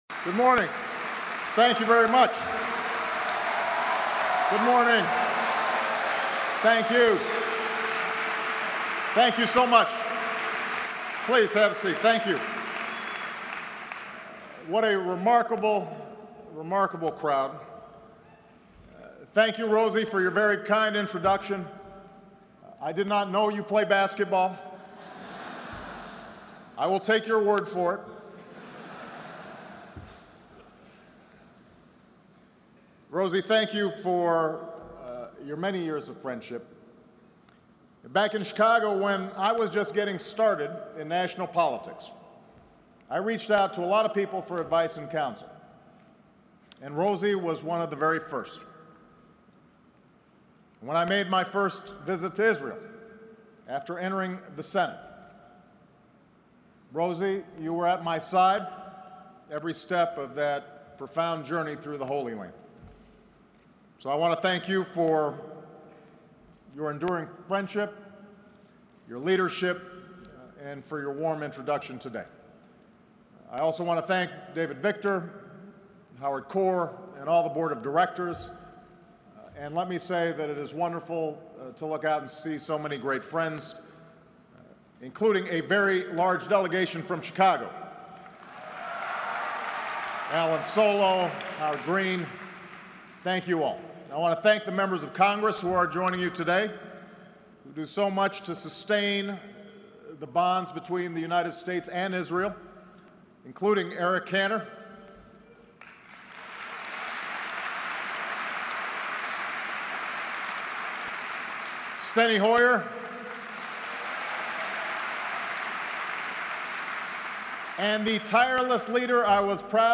U.S. President Barack Obama speaks at the American Israel Public Affairs Committee Conference